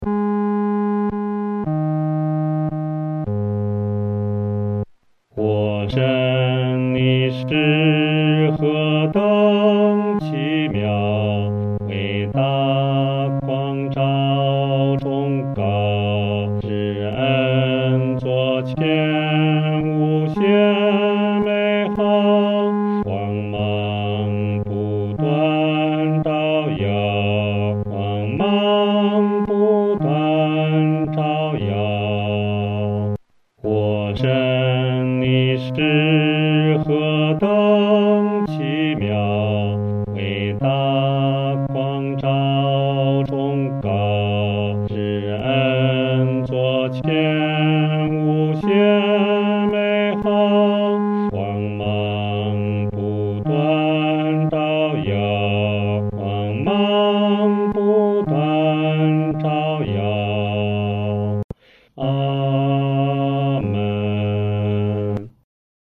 男低